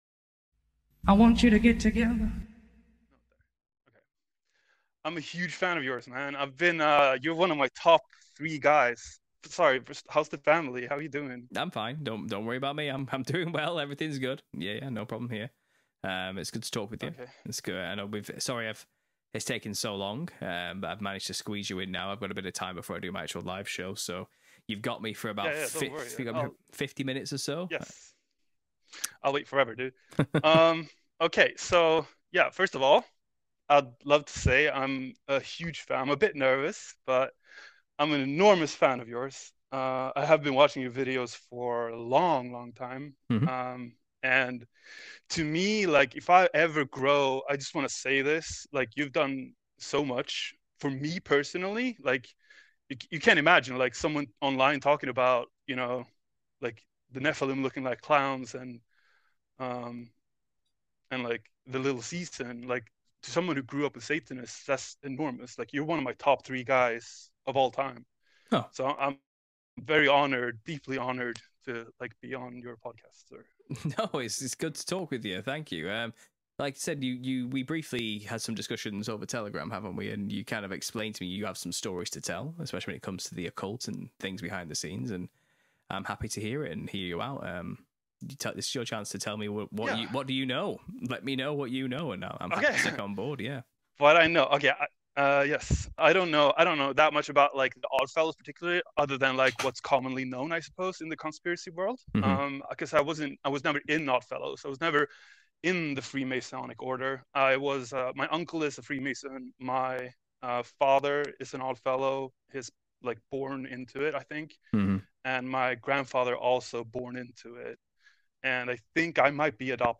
Join me for a Live Q&A where there won't be a dull moment! Today, I want to discuss AI friends and the dystopian advertisements that are emerging, trying to sell them to us.